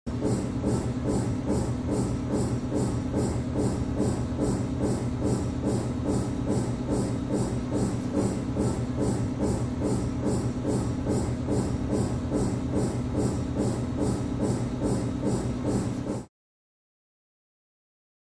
MRI; Scan In Progress, Various Knocks, Low Beeps, Machine Drone In Background